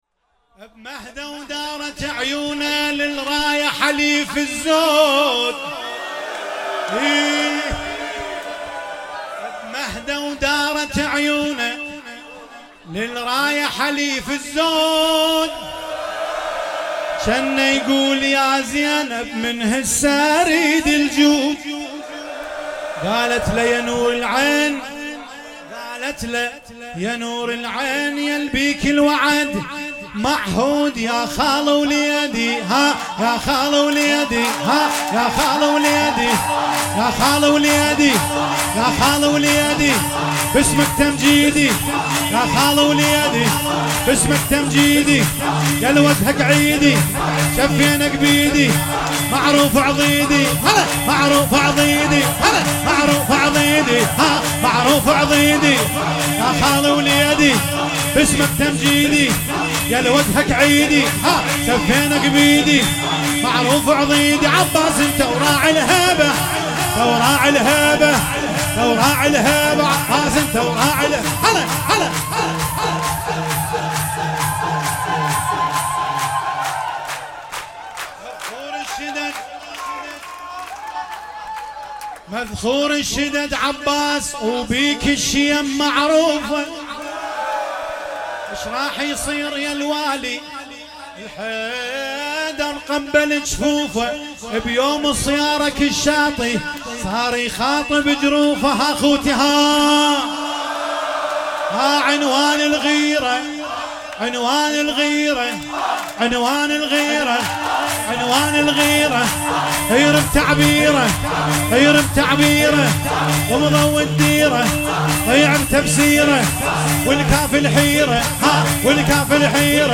20 فروردین 98 - هیئت محبان الائمه - حوسه - مهدو دارت عيون
ولادت سرداران کربلا